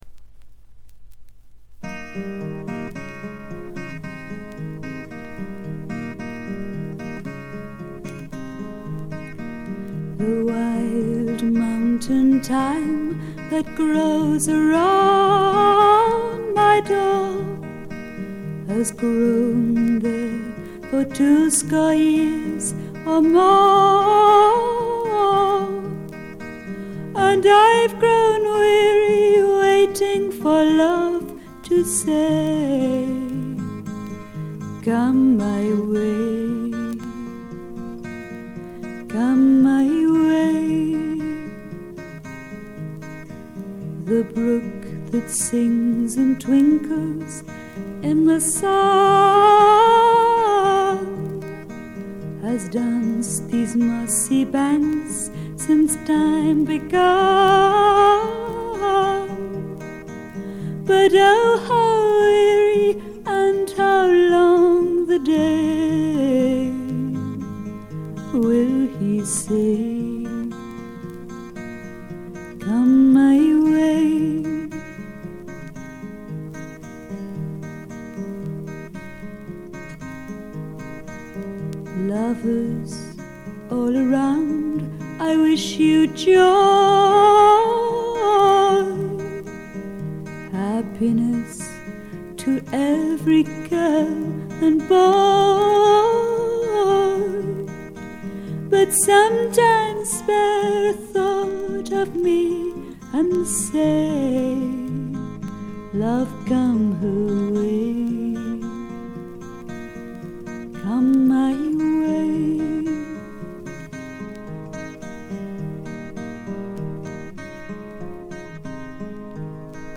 全体に軽微なバックグラウンドノイズ。ところどころでチリプチ。
英国フィメール・フォークの大名作でもあります。
内容はというとほとんどがトラディショナル・ソングで、シンプルなアレンジに乗せた初々しい少女の息遣いがたまらない逸品です。
モノラル盤です。
試聴曲は現品からの取り込み音源です。